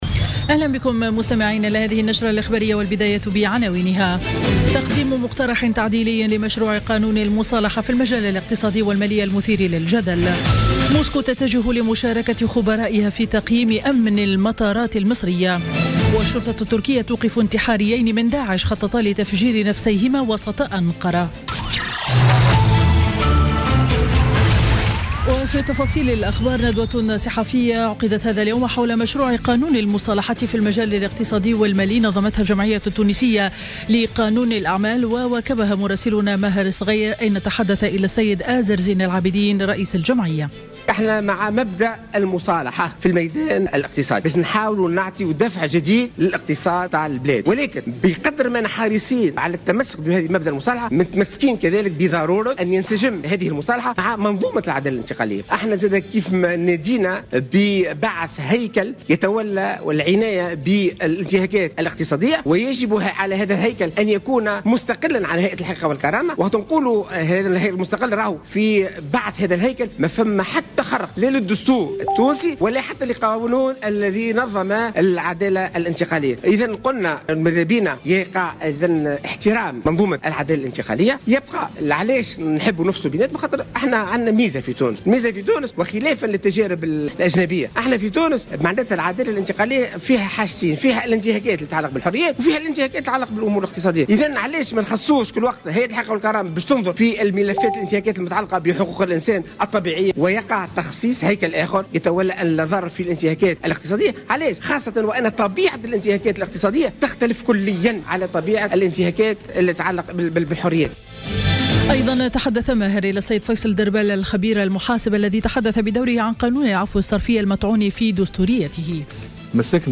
نشرة أخبار منتصف النهار ليوم الأربعاء 30 ديسمبر 2015